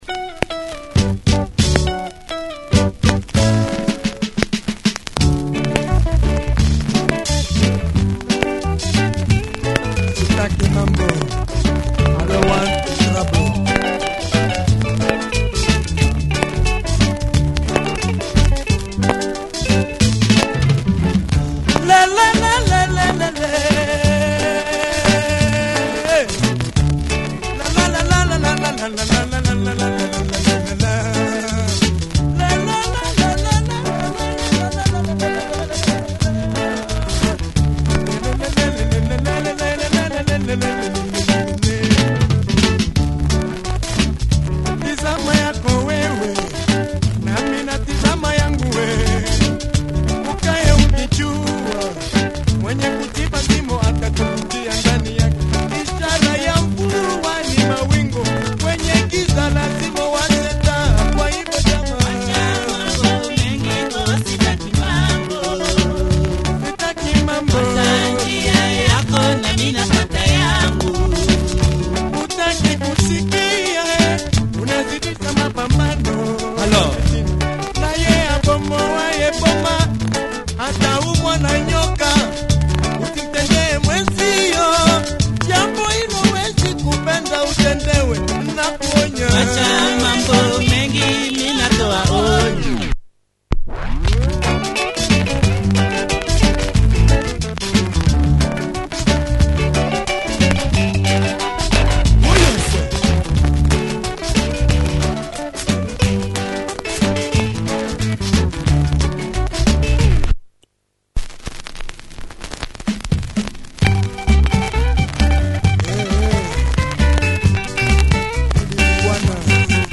Great tempo change on